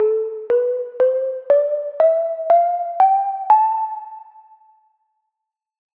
Aeolian
2025-kpop-scale-aeo.mp3